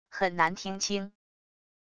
很难听清wav音频